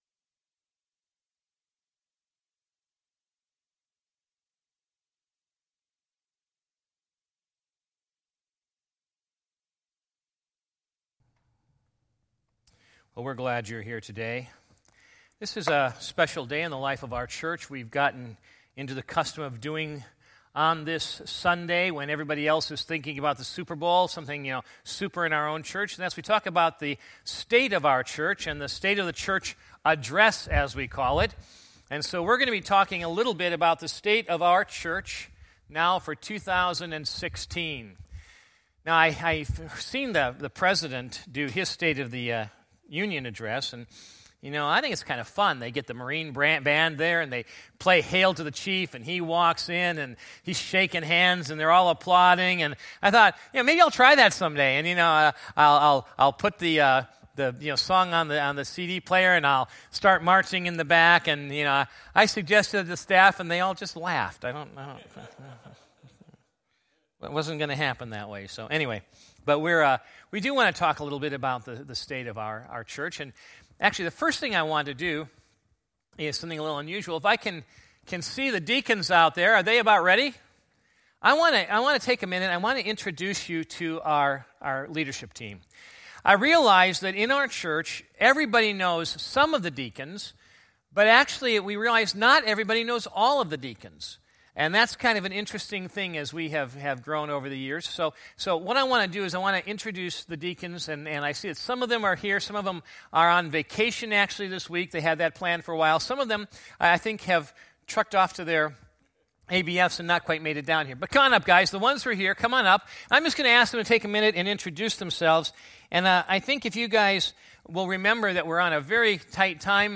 with selected Deacons and Staff
Sunday Morning Message